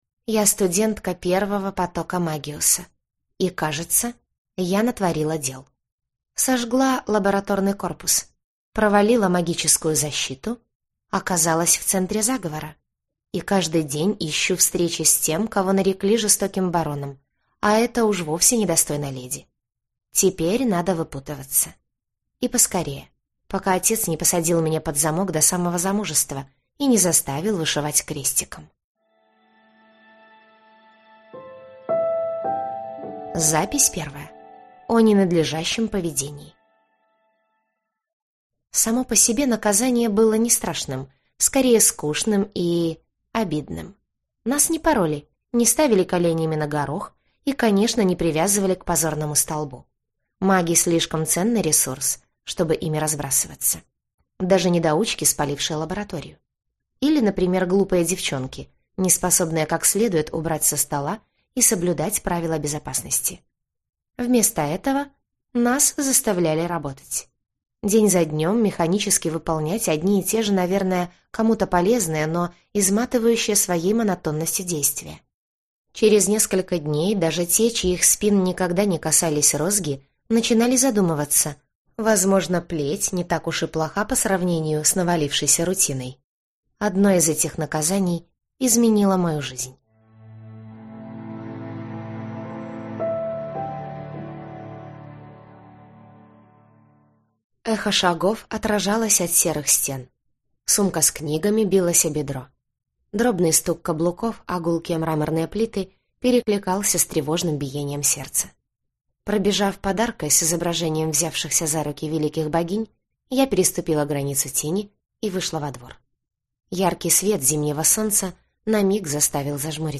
Аудиокнига Табель первокурсницы | Библиотека аудиокниг
Прослушать и бесплатно скачать фрагмент аудиокниги